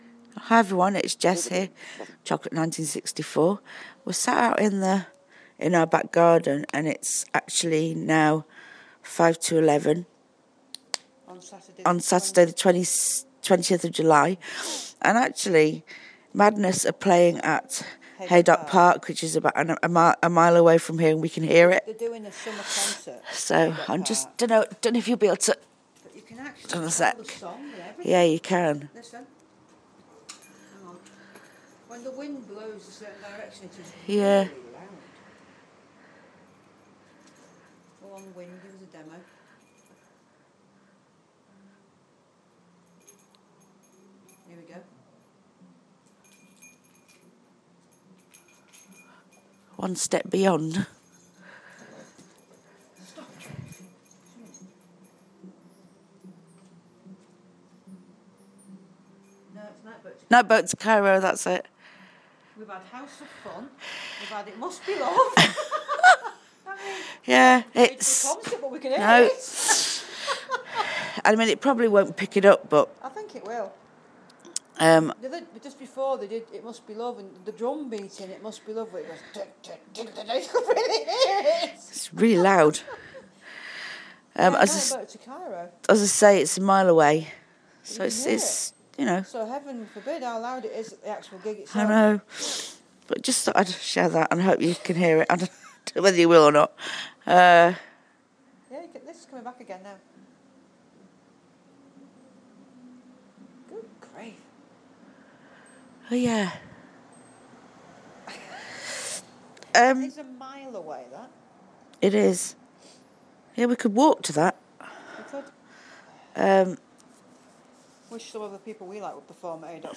The things you can hear from your back garden